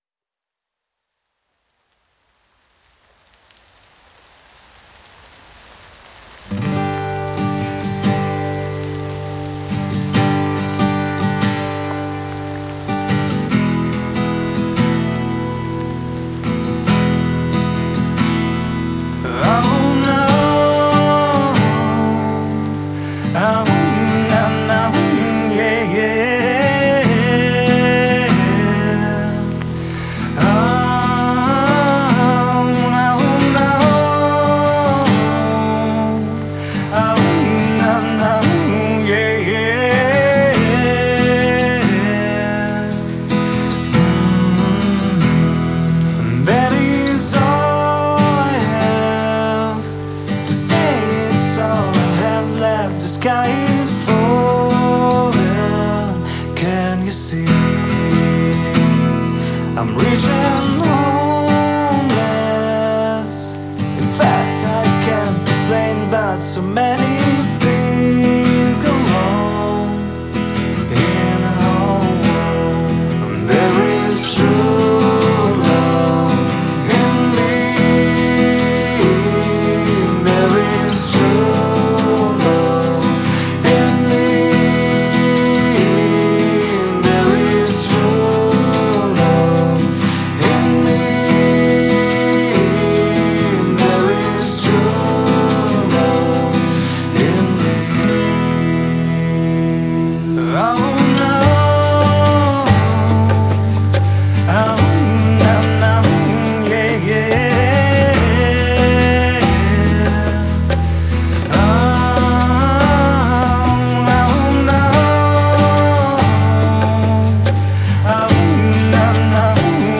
Вот на сильверах вся песня,я их штук так 7 инстанций навешивал,уж и не помню.